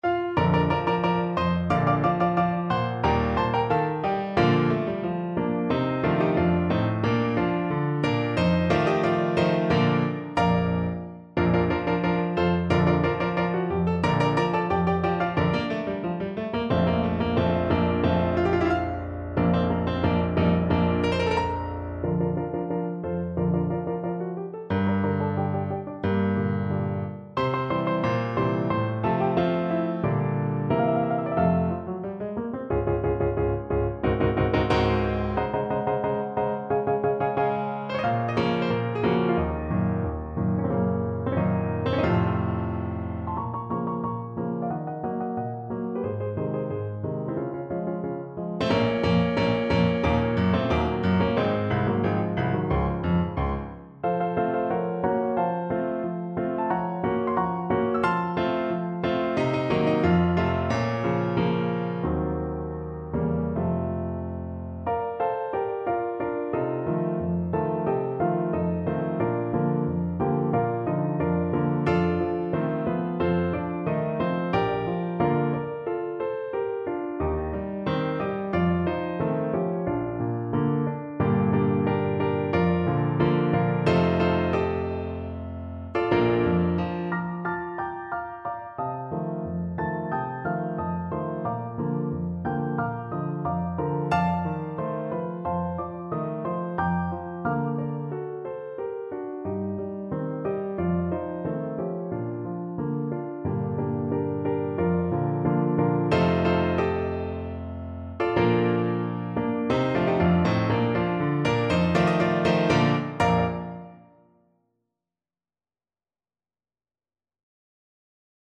Allegretto =90
2/4 (View more 2/4 Music)